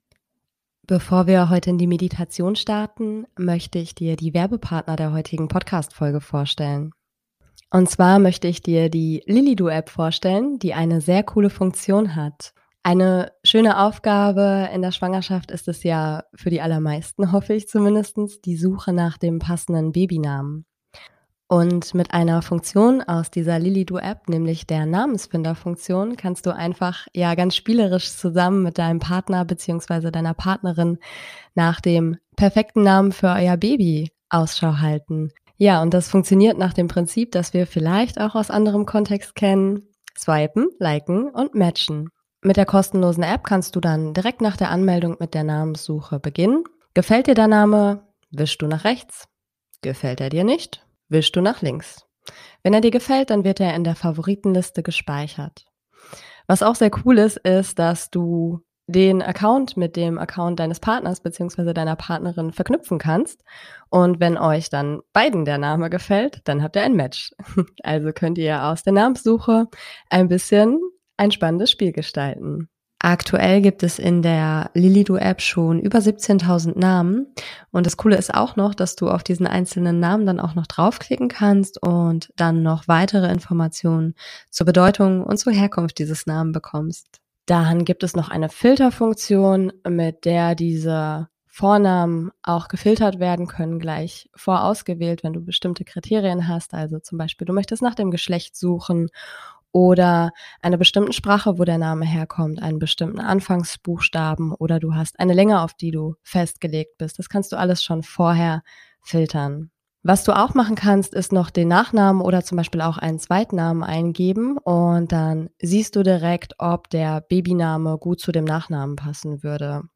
#087 - Meditation bei Schwangerschaftsübelkeit ~ Meditationen für die Schwangerschaft und Geburt - mama.namaste Podcast